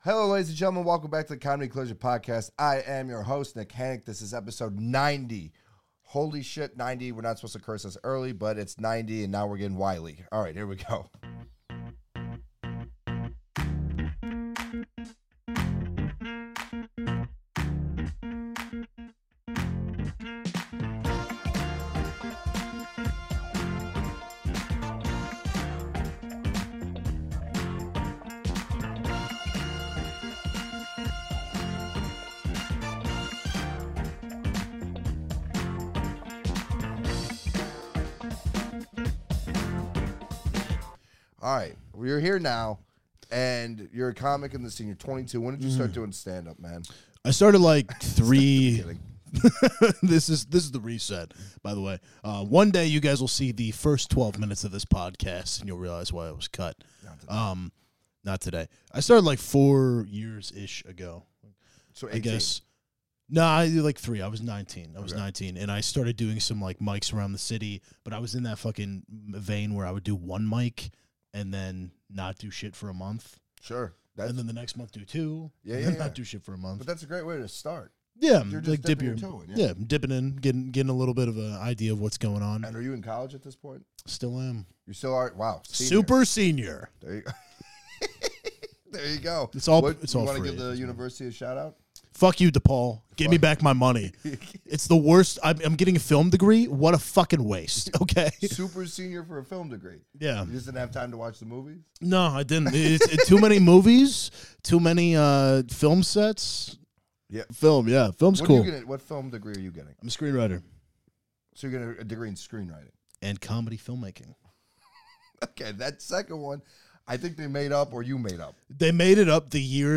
Be a guest on this podcast Language: en Genres: Comedy , Comedy Interviews Contact email: Get it Feed URL: Get it iTunes ID: Get it Get all podcast data Listen Now...